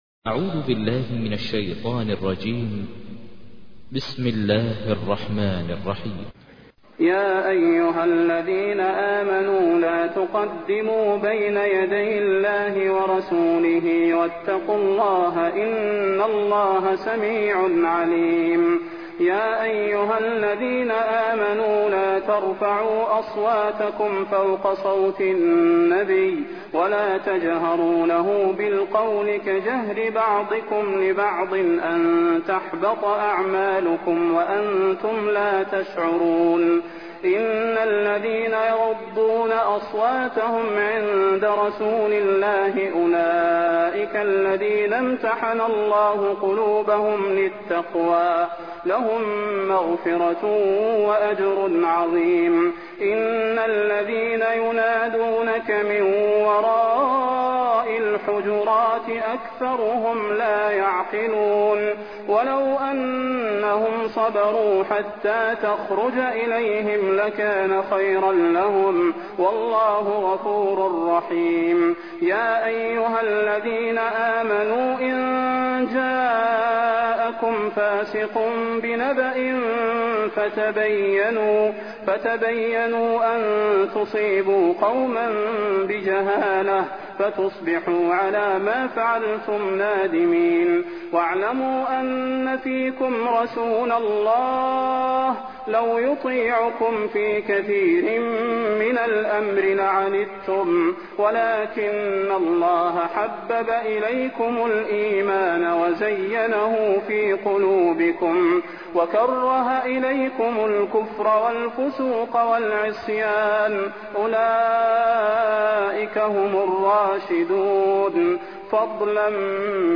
تحميل : 49. سورة الحجرات / القارئ ماهر المعيقلي / القرآن الكريم / موقع يا حسين